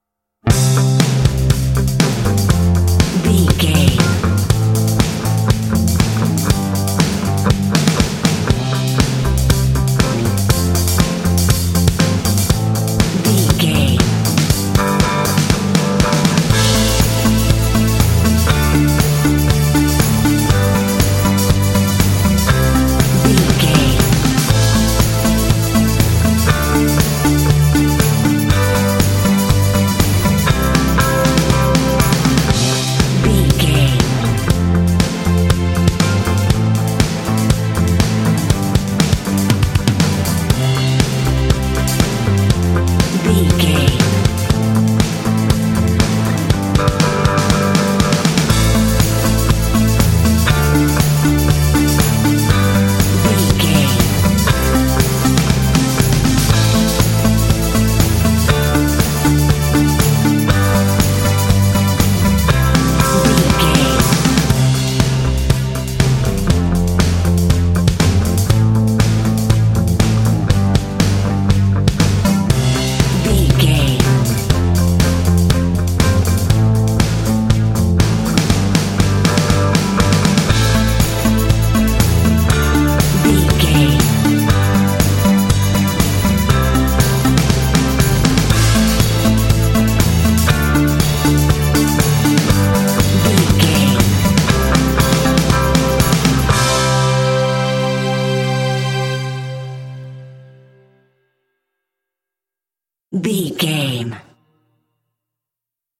Uplifting
Ionian/Major
driving
bouncy
cheerful/happy
percussion
drums
bass guitar
synthesiser
electric guitar
strings
80s
rock
pop
alternative rock
indie